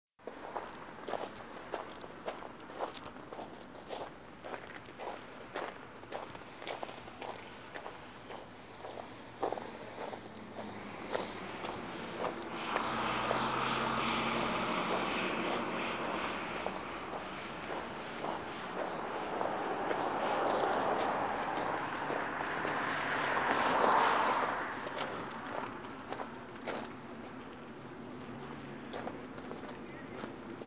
Field Recording Numero Uno
Location: Front St, Hempstead, NY
Sounds Featured: Footsteps in the snow, cars passing, my own breathing, the tap-tap-tapping of the microphone against my jacket